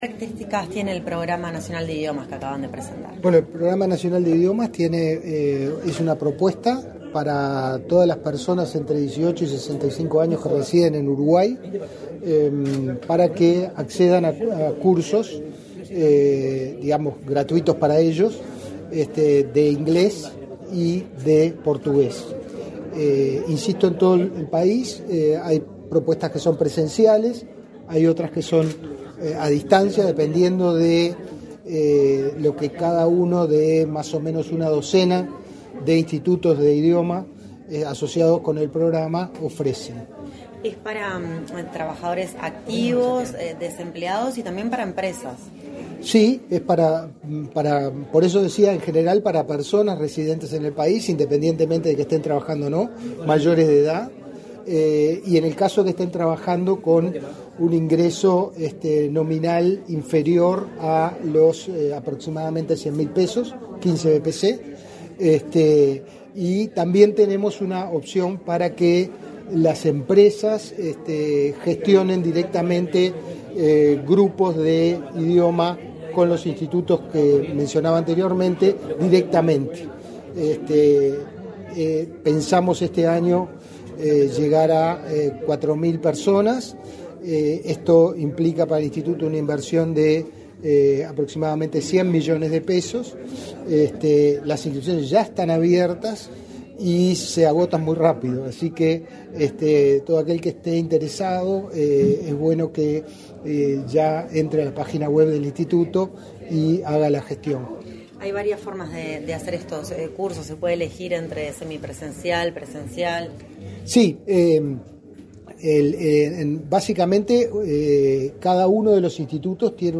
Declaraciones a la prensa del director general del Inefop, Pablo Darscht
Declaraciones a la prensa del director general del Inefop, Pablo Darscht 04/02/2025 Compartir Facebook X Copiar enlace WhatsApp LinkedIn Tras participar en el acto de lanzamiento de la 3.ª edición del programa Idiomas, impulsado por el Instituto Nacional de Empleo y Formación Profesional (Inefop), este 4 de febrero, el director general del organismo, Pablo Darscht, realizó declaraciones a la prensa.